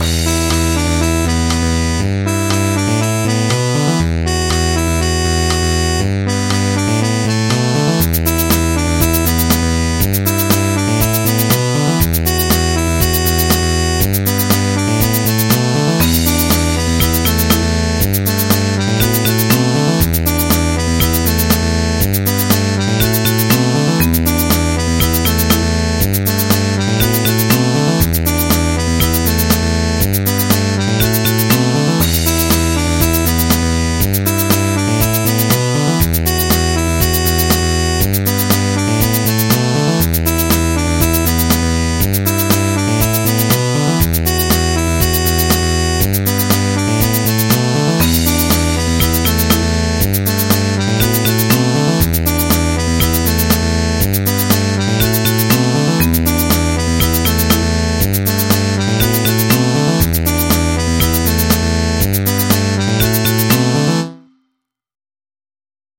MIDI 6.88 KB MP3